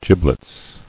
[gib·lets]